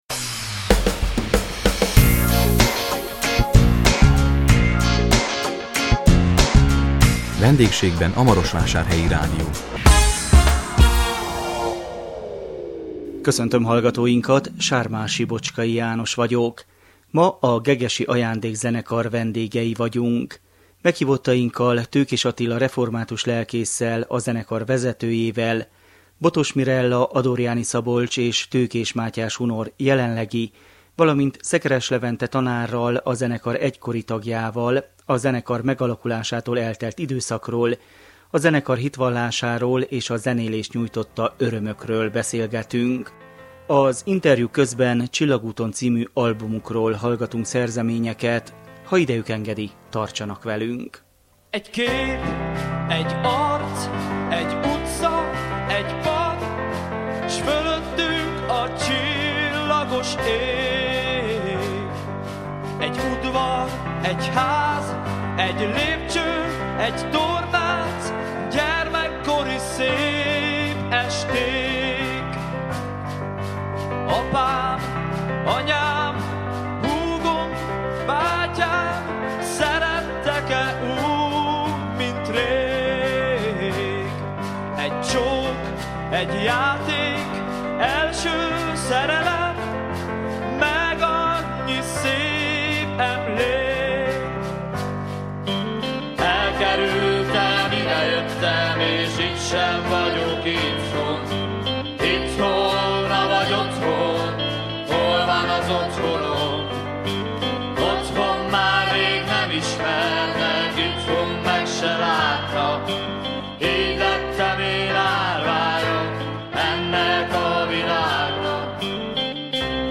A 2017 december 28-án jelentkező Vendégségben a Marosvásárhelyi Rádió című műsorunkban a Maros megyei Gegesből jelentkeztünk, az Ajándék Zenekar vendégei voltunk.